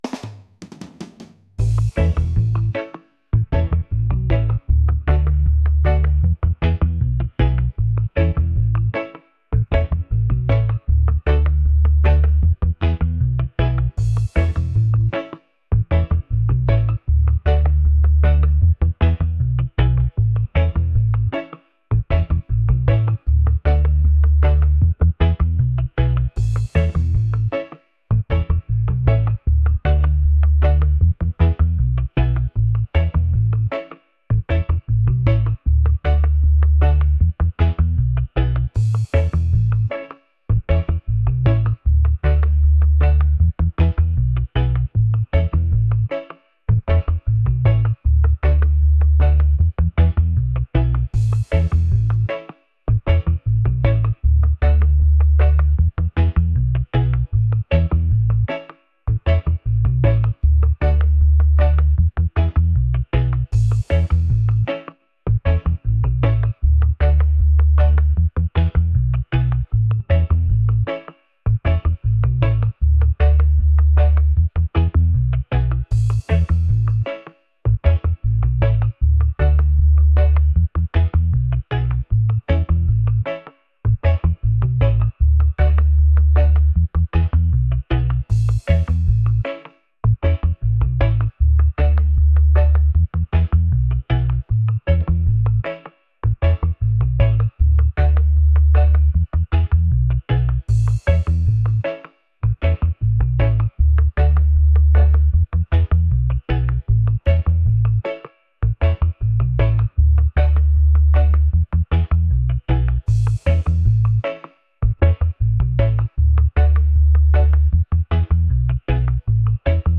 upbeat | positive | reggae